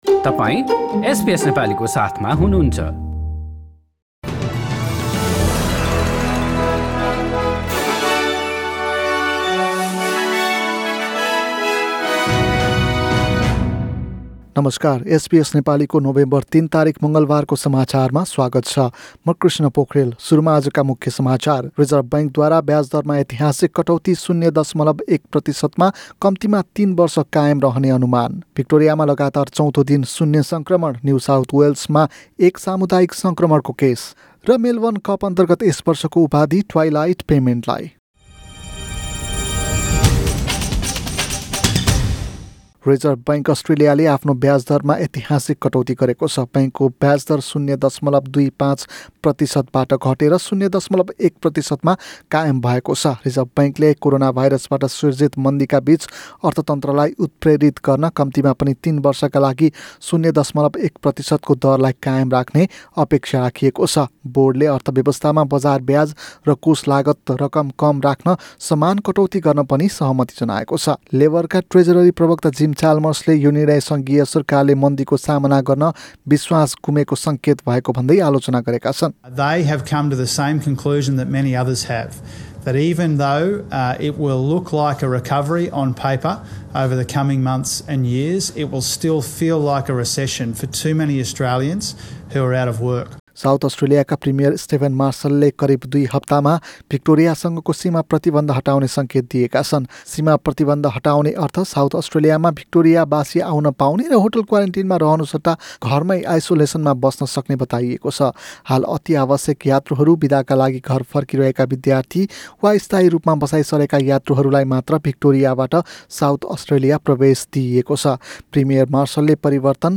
SBS Nepali Australia News: Tuesday 3 November 2020
Listen to the latest news headlines in Australia from SBS Nepali Radio.